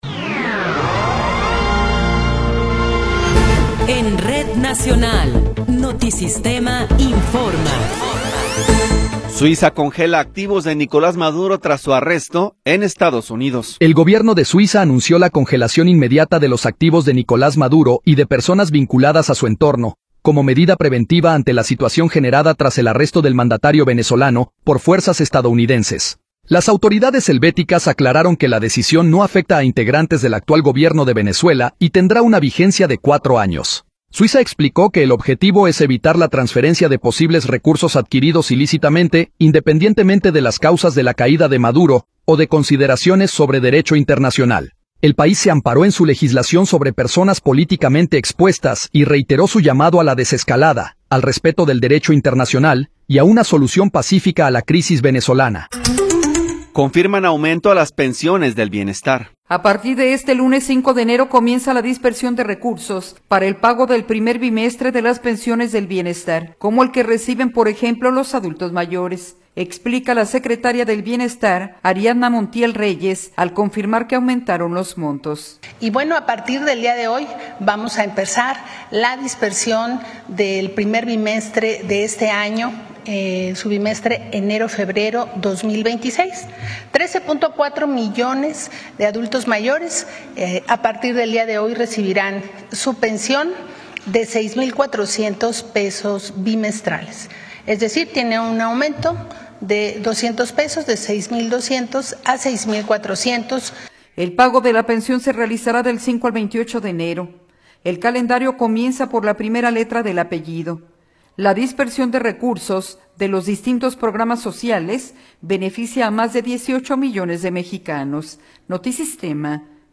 Noticiero 11 hrs. – 5 de Enero de 2026